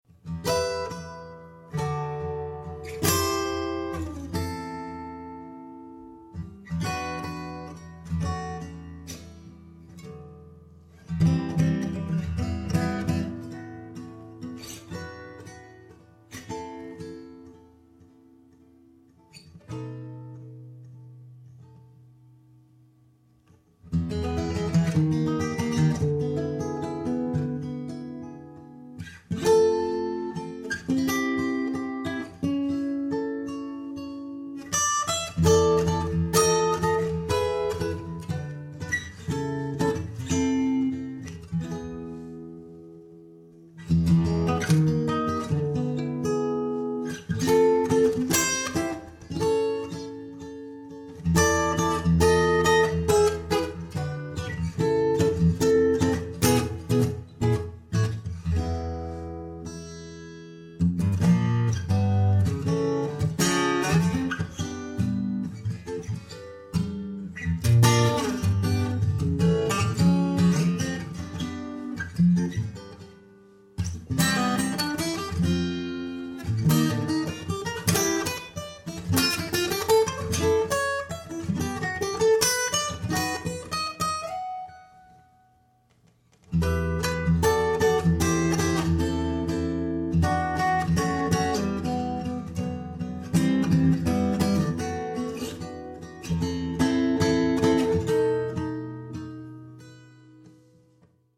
Akustische Gitarren